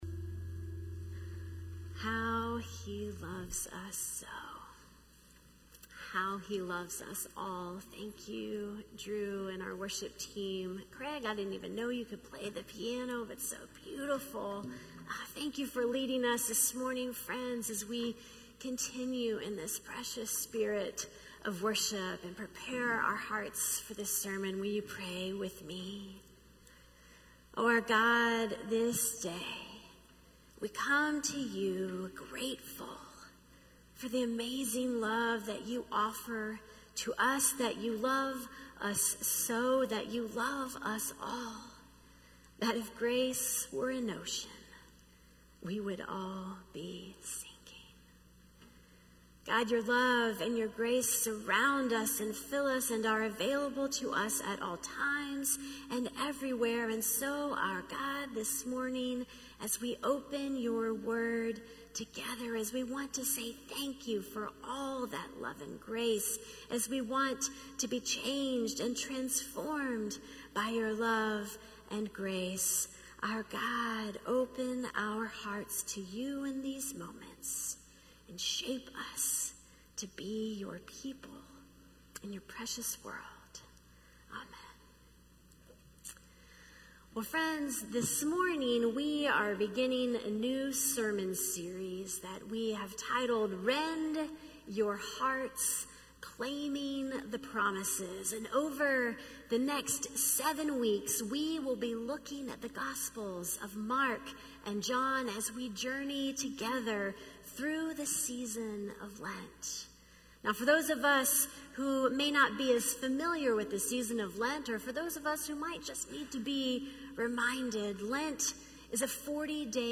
A message from the series "Rend Your Heart."